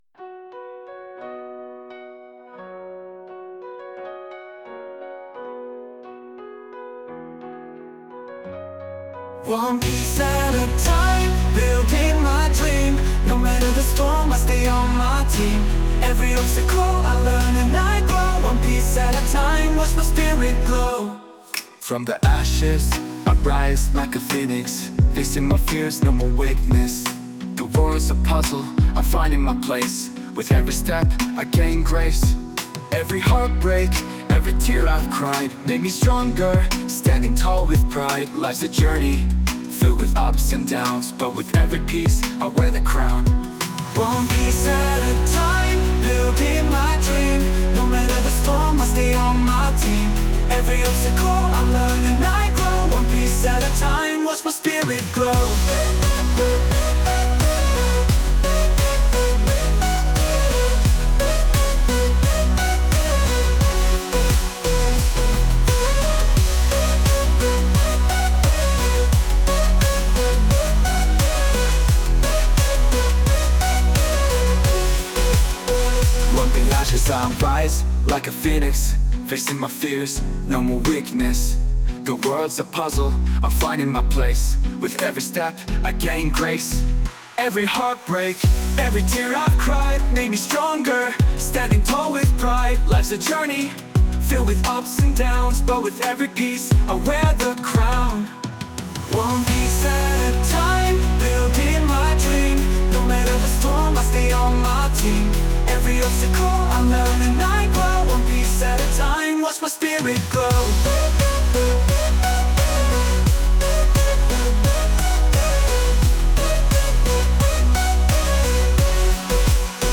歌詞ありフリー音源。